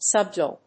音節sub・du・al 発音記号・読み方
/səbd(j)úːəl(米国英語)/